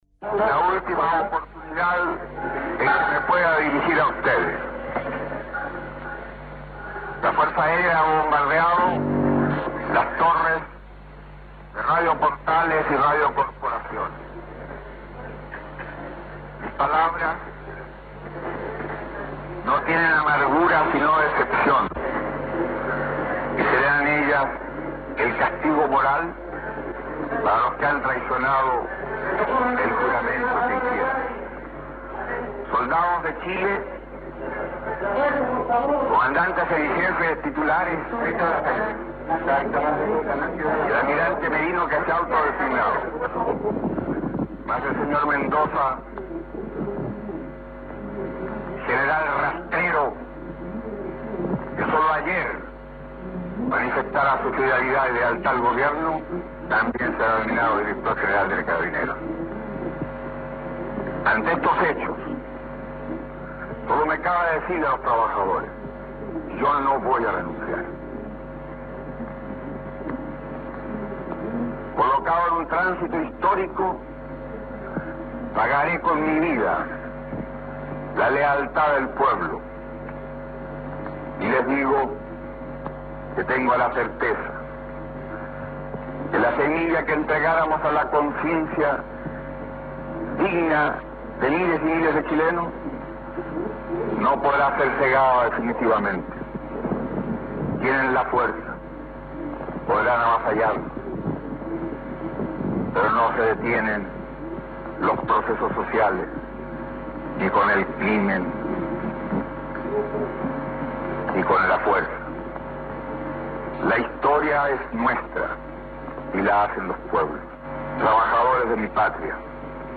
Discorso Di S. Allende A Radio Magallanes 11-9-73.mp3